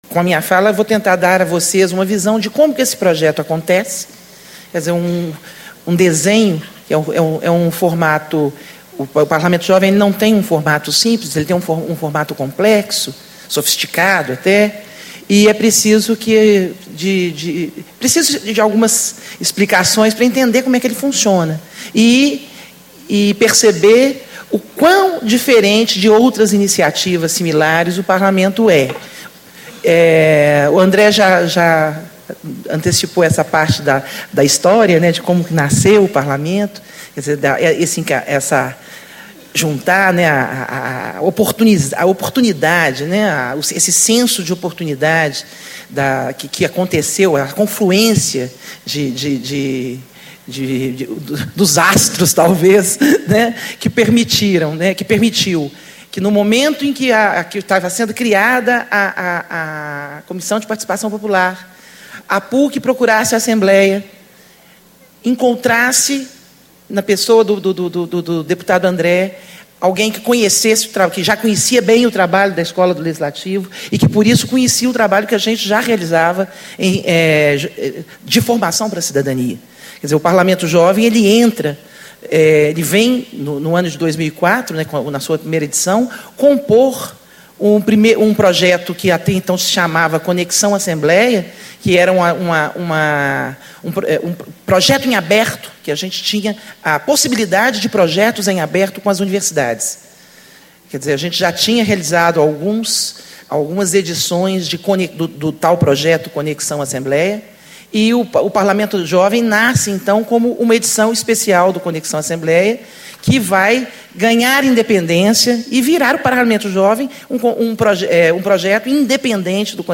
Discursos e Palestras